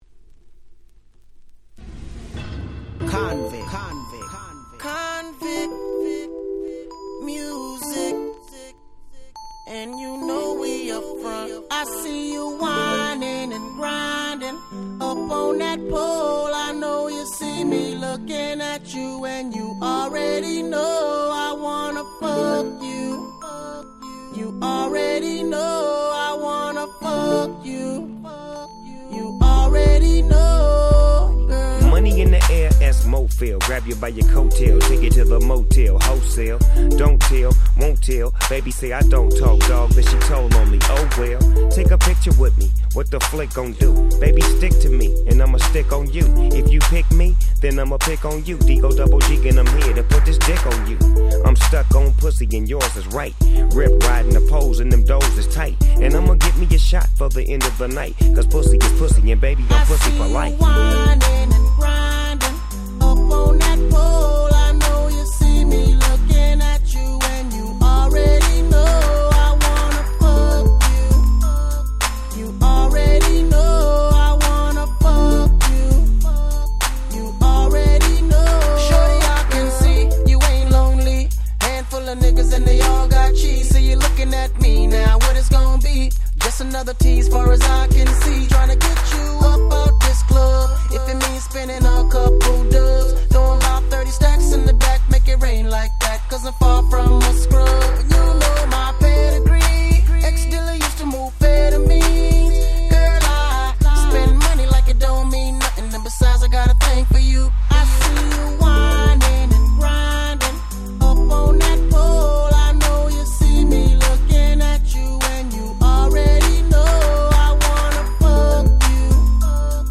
06' Super Hit R&B !!